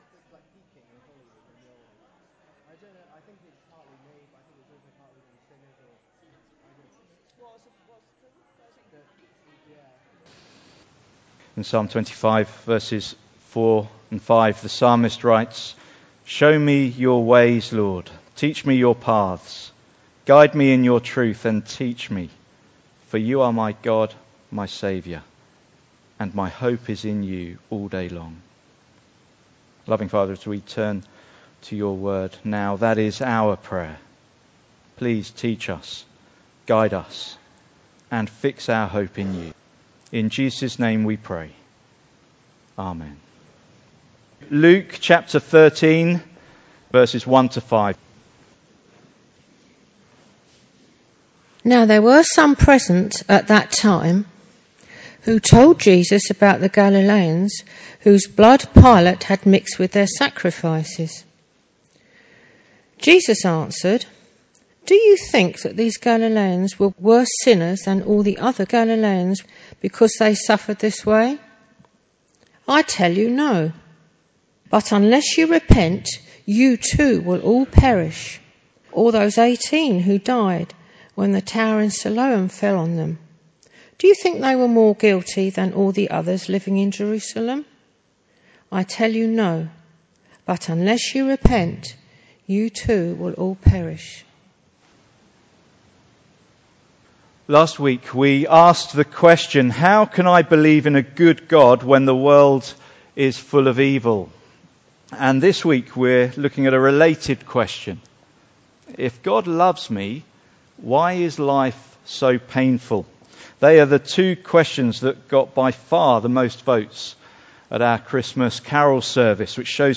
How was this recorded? Passage: Luke 13:1-5 Service Type: Sunday Morning